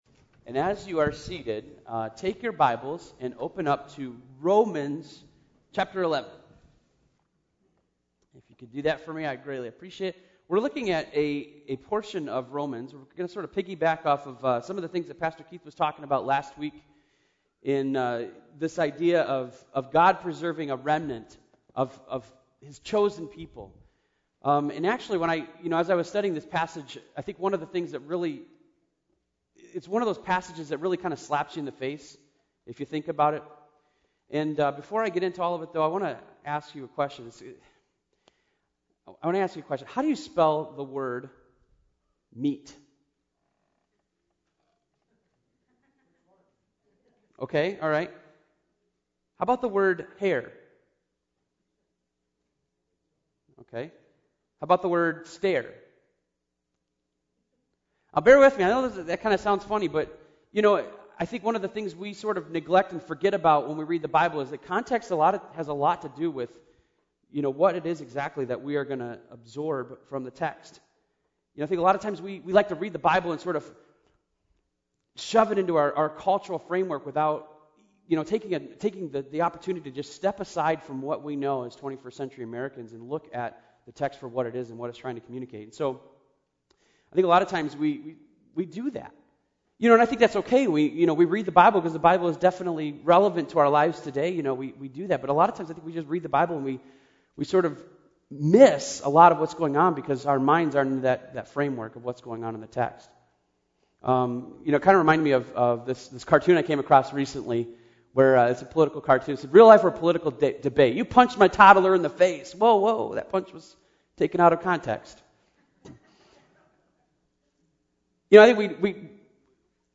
sermon-10-2-11.mp3